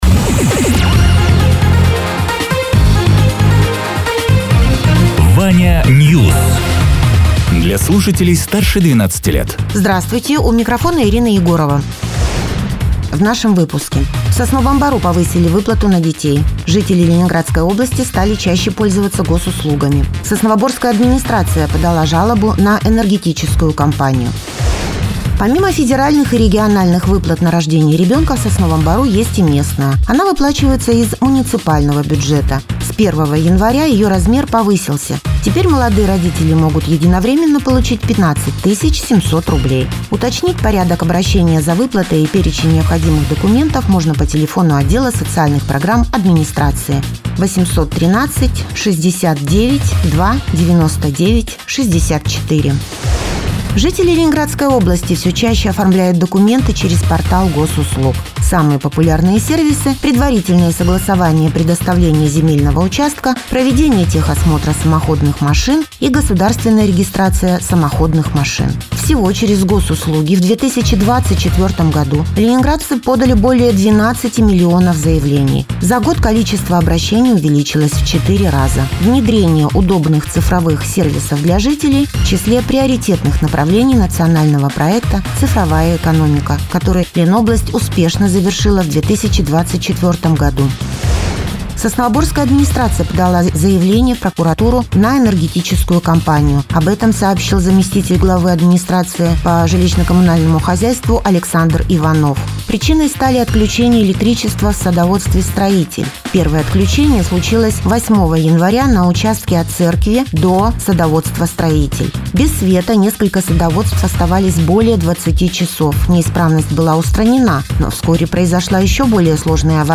Радио ТЕРА 18.01.2025_08.00_Новости_Соснового_Бора